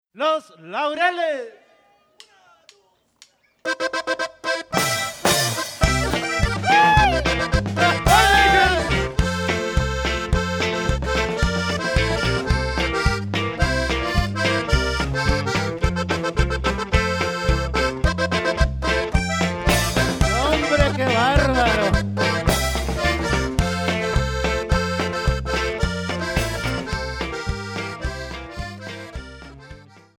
accordion
bajo sexto, vocals
bass guitar